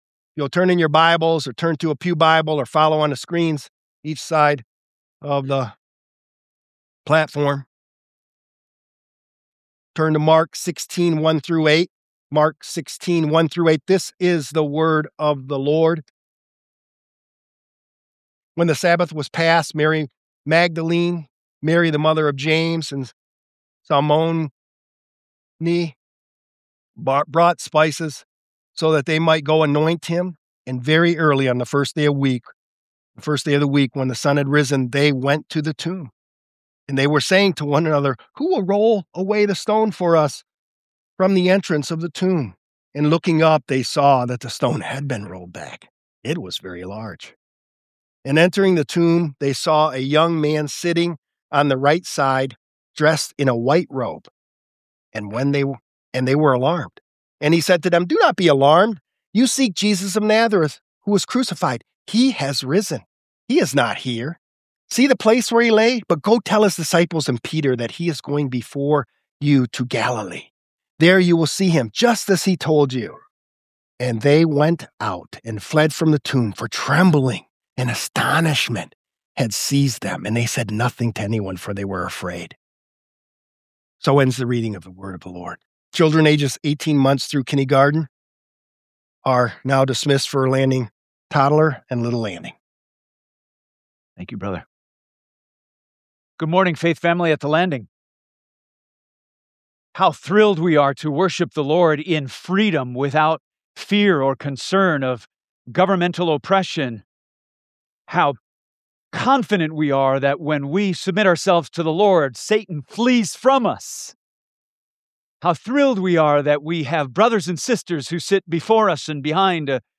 Download Download Reference Mark 16: 1-8 Mark Current Sermon Seek Jesus Who Was Crucified: He Is Risen!